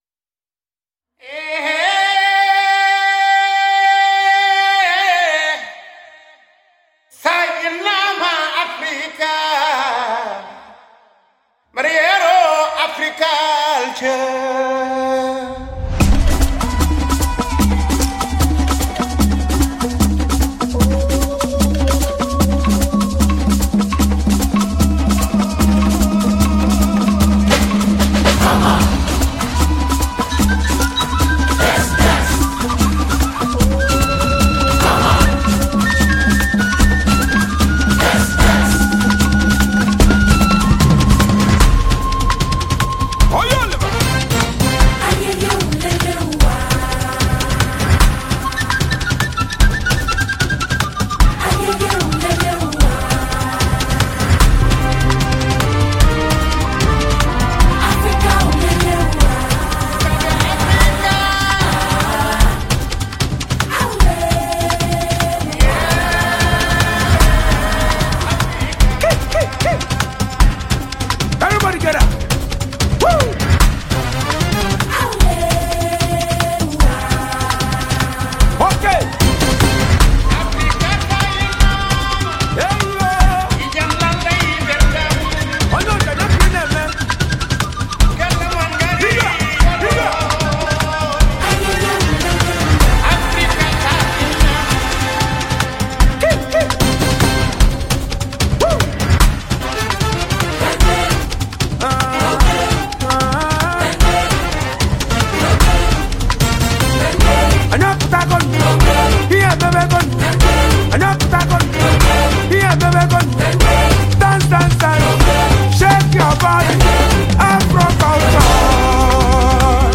highlife, afrobeat, and traditional African sounds
bold, proud, and soulful